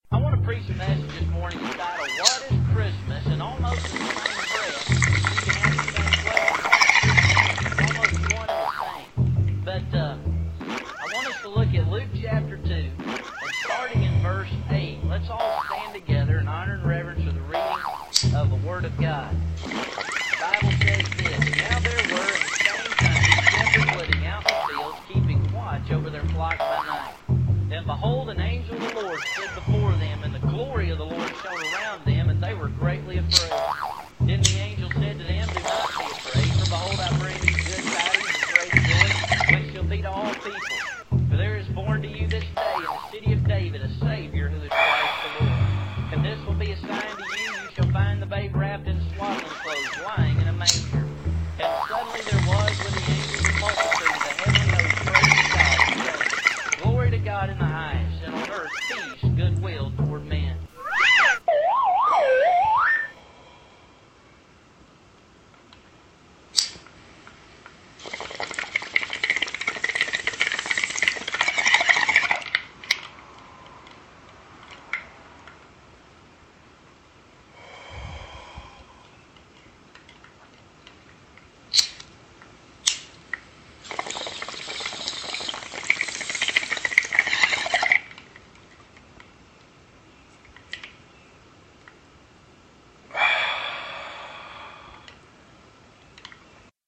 Tags: silly nutty weird noise sound collage